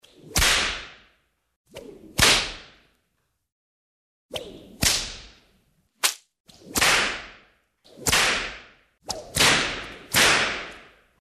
На этой странице собраны разнообразные звуки удара кнутом: от резких щелчков до протяжных свистов.
Звук удара кнутом ковбоя